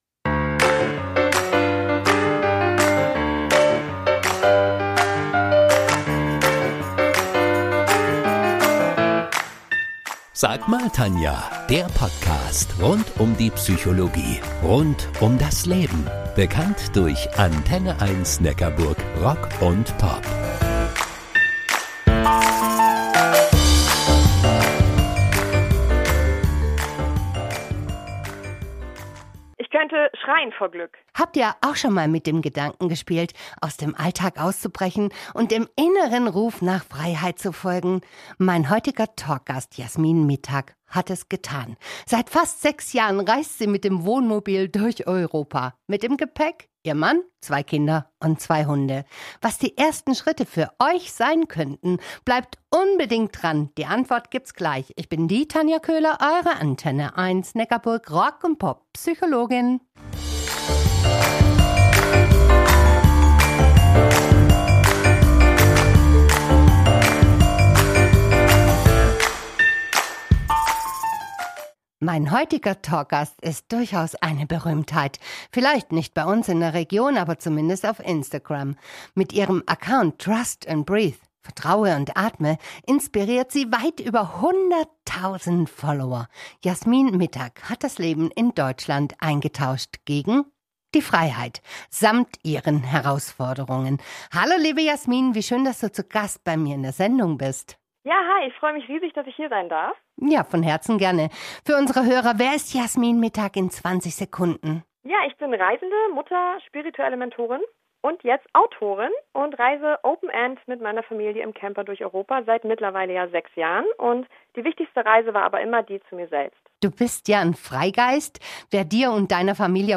ACHTUNG! Diese Podcast-Episode ist ein Mitschnitt der